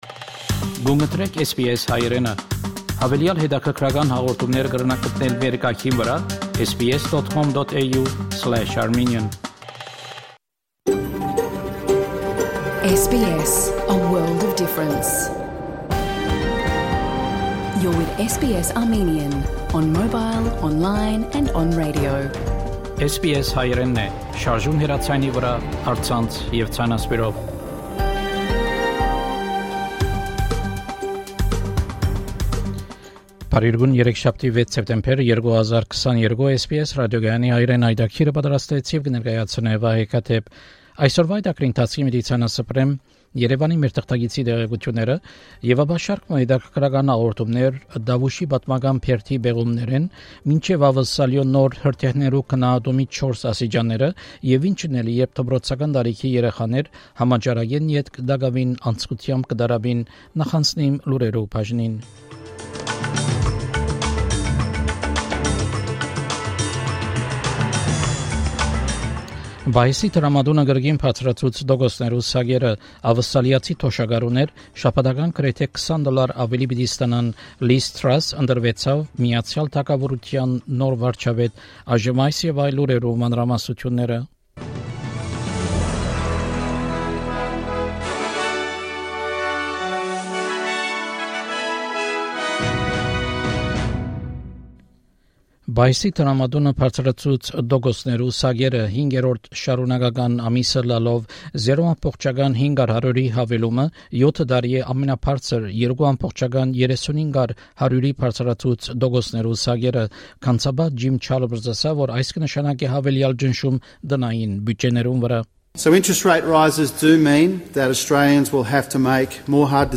SBS Armenian news bulletin – 6 September 2022
SBS Armenian news bulletin from 6 September 2022 program.